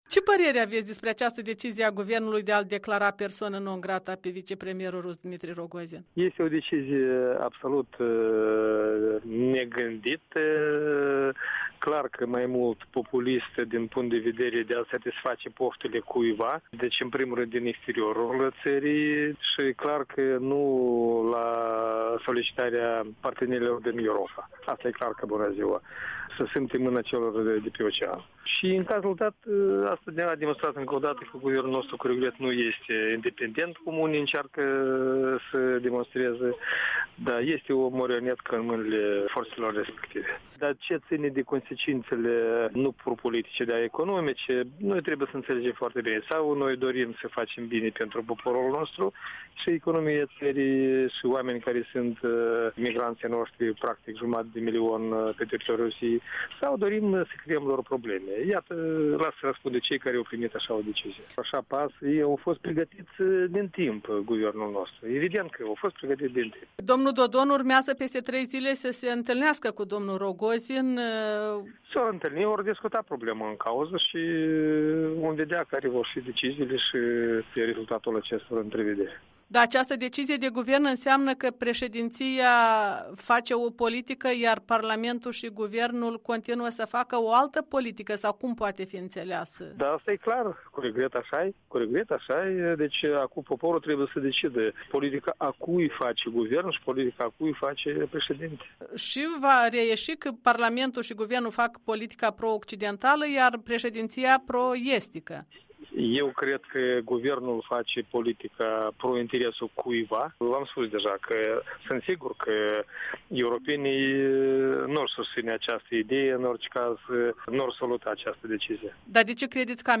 Interviu cu deputatul socialist Vladimir Țurcanu